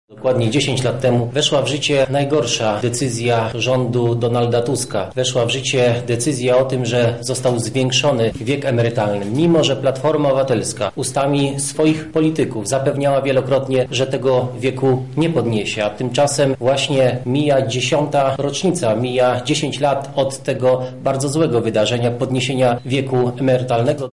-mówi Sylwester Tułajew, poseł PiS.